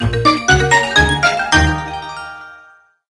A song
Ripped from the game